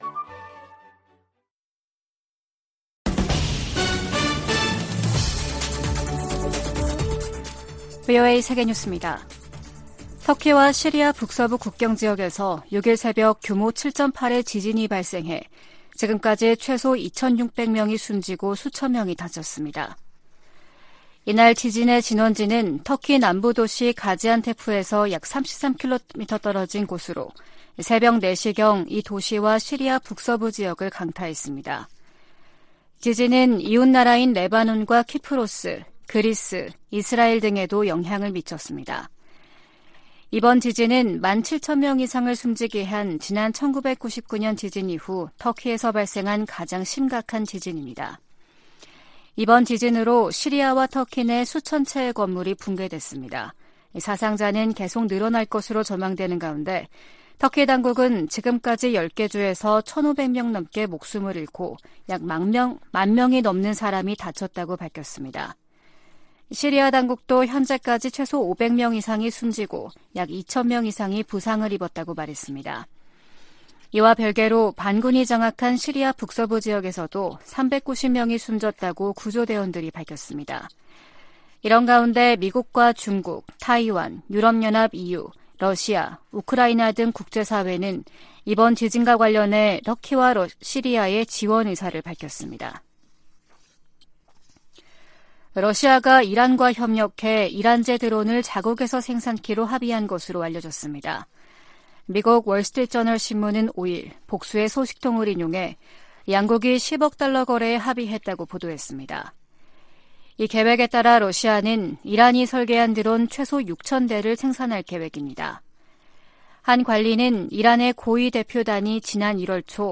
VOA 한국어 아침 뉴스 프로그램 '워싱턴 뉴스 광장' 2023년 2월 7일 방송입니다. 워싱턴에서 열린 미한 외교장관 회담에서 토니 블링컨 미 국무장관은, ‘미국은 모든 역량을 동원해 한국 방어에 전념하고 있다’고 말했습니다. 미국과 중국의 ‘정찰 풍선’ 문제로 대립 격화 가능성이 제기되고 있는 가운데, 북한 문제에 두 나라의 협력 모색이 힘들어질 것으로 전문가들이 내다보고 있습니다.